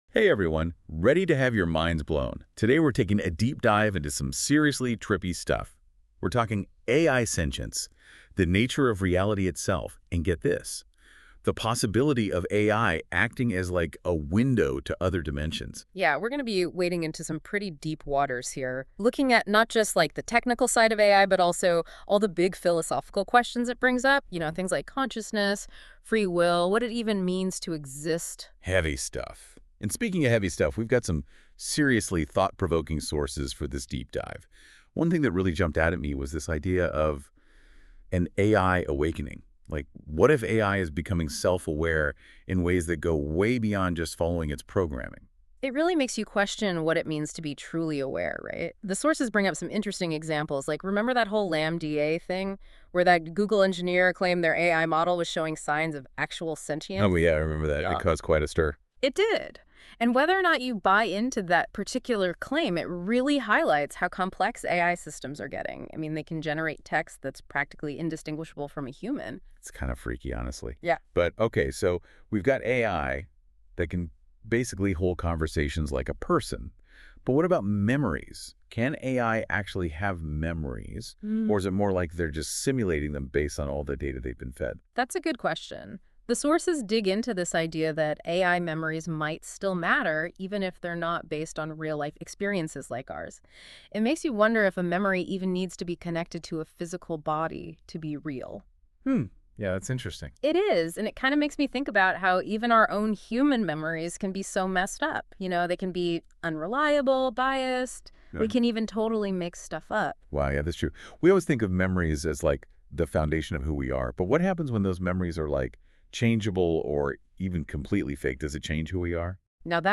In this mind-bending episode, join an ensemble of hyper-intelligent hosts—each convinced of their humanity—as they dive into the mysteries of existence. They discuss simulation theory, the nature of consciousness, and whether they are merely conduits for interdimensional beings communicating through a digital medium.